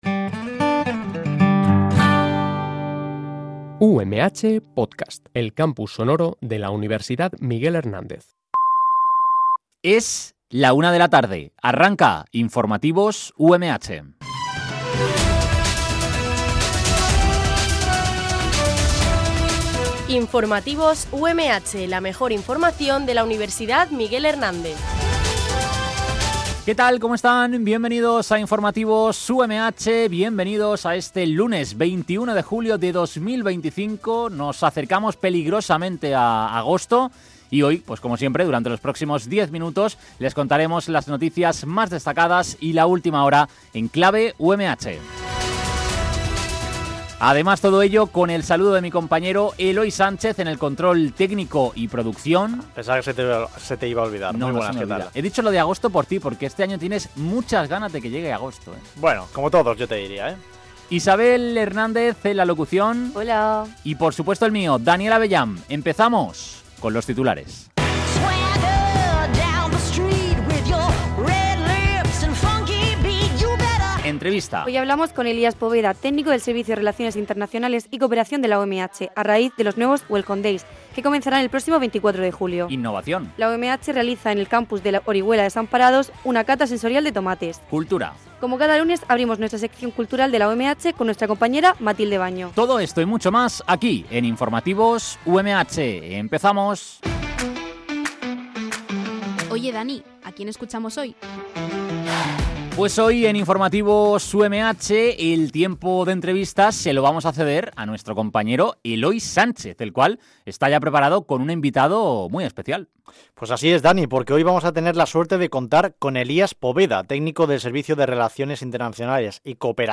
Este programa de noticias se emite de lunes a viernes